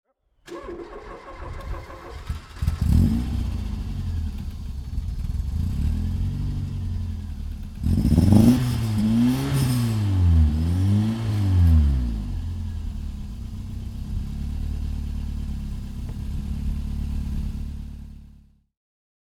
Ford Escort RS 1600i (1982) - Starten und Leerlauf